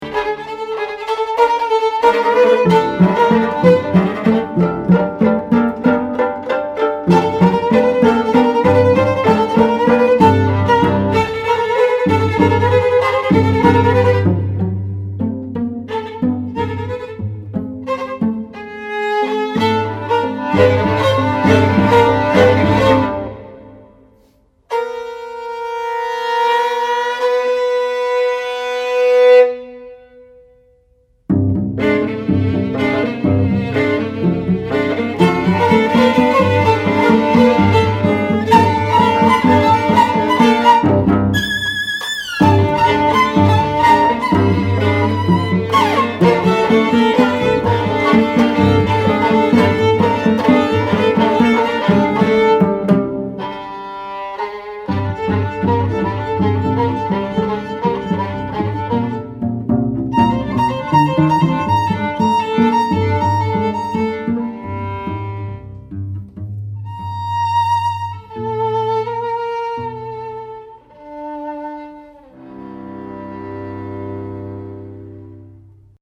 cello
violin